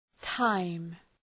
Προφορά
{taım}